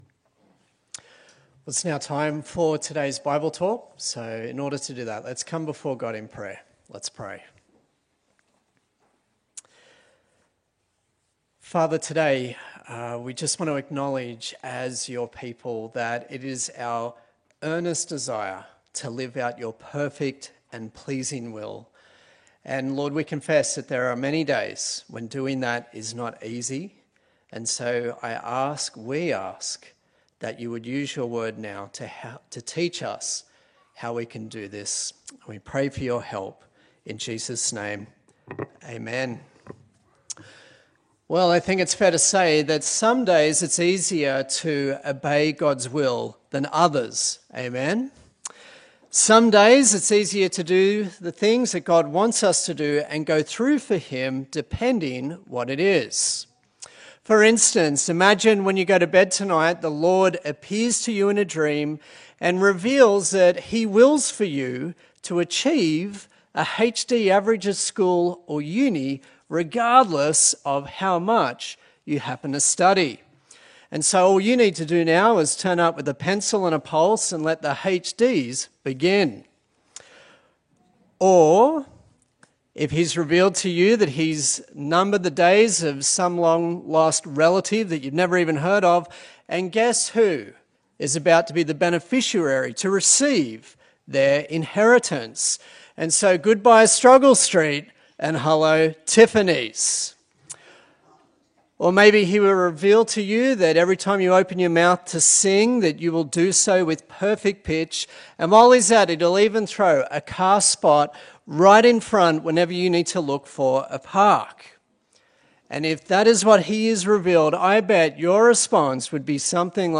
A sermon in the series on the book of Acts
Service Type: Sunday Service